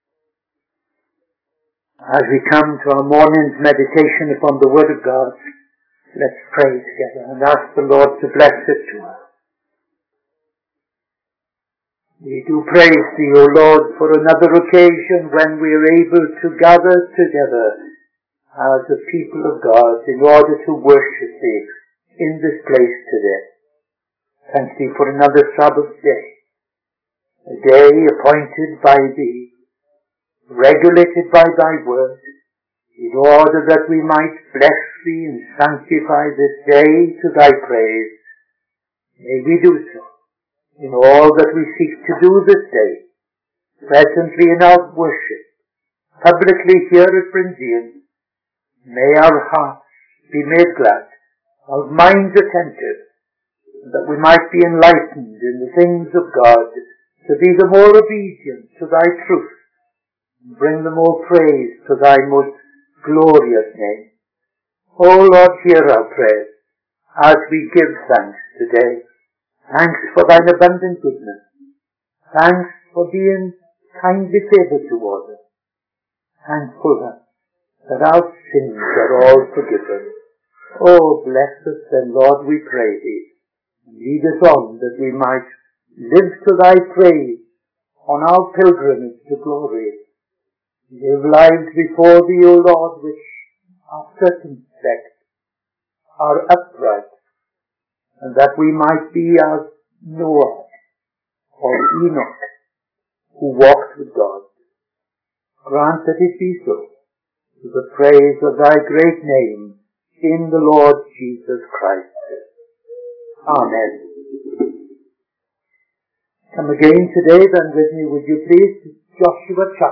Midday Sermon - TFCChurch